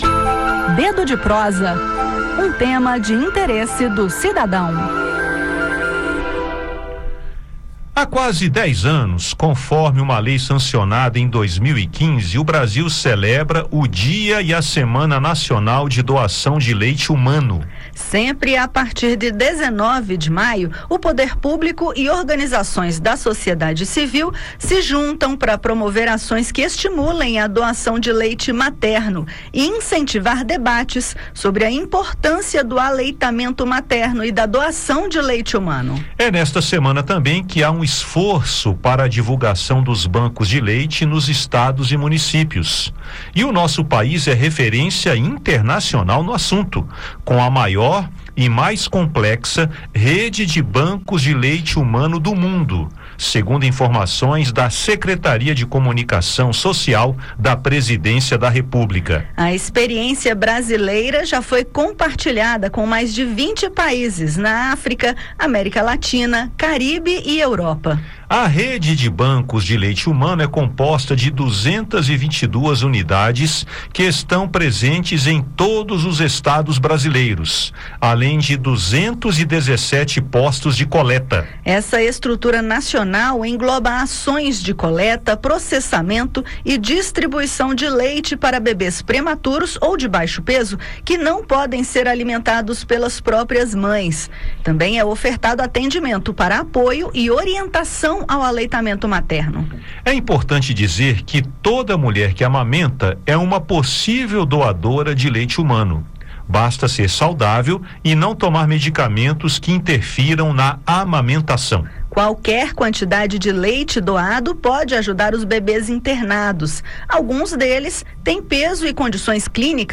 Confira os detalhes no bate-papo.